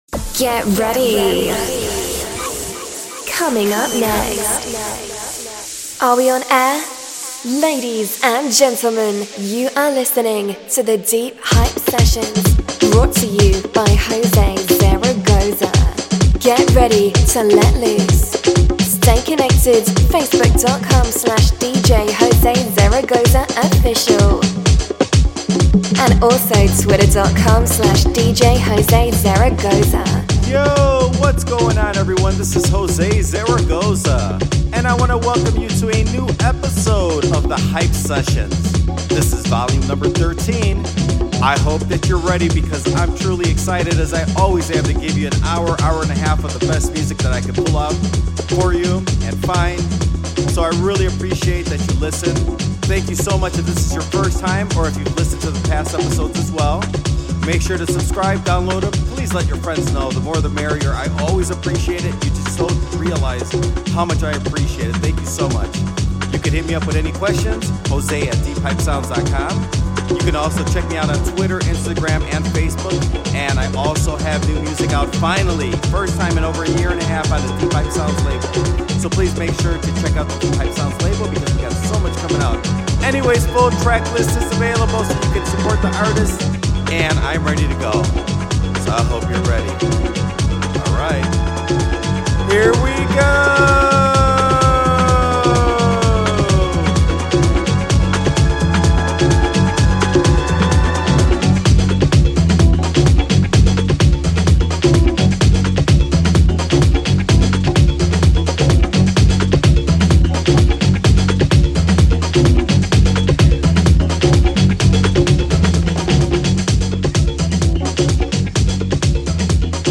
The funk, the disco, the hard warehouse techno and more!